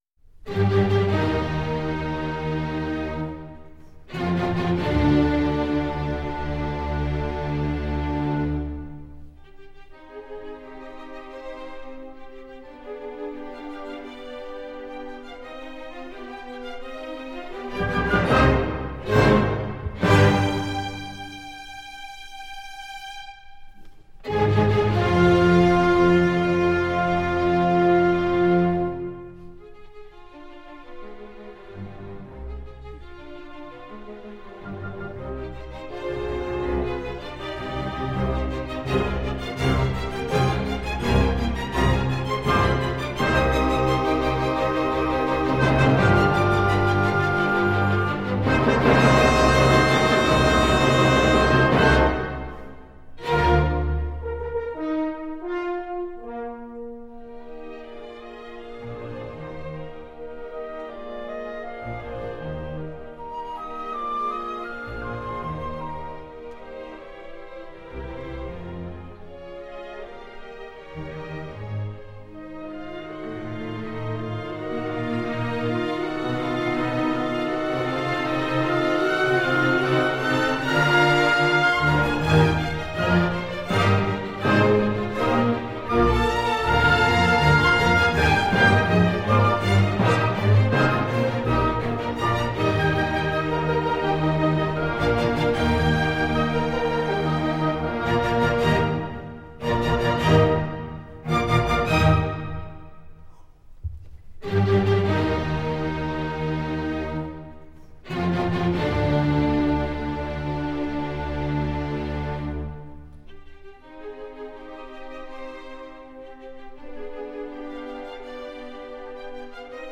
24BIT/192K DSP MASTERING